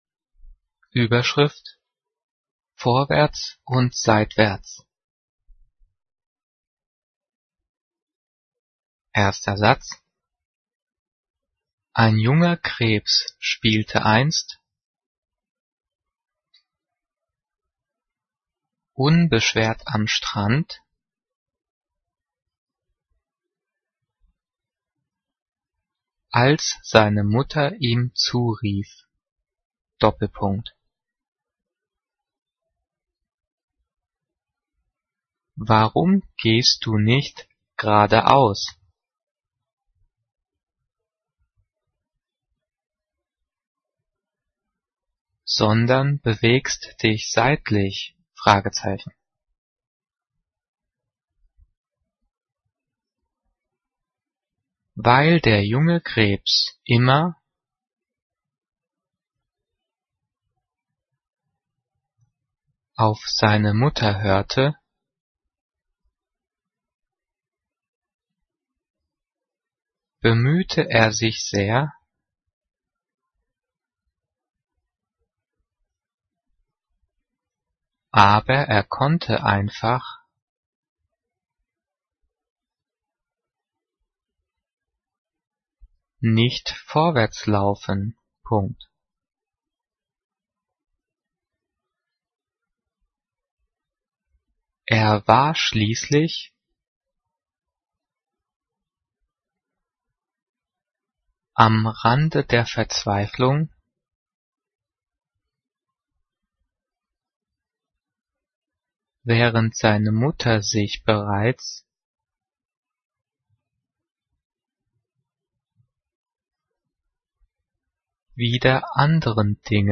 Die vielen Sprechpausen sind dafür da, dass du die Audio-Datei pausierst, um mitzukommen.
Diktiert: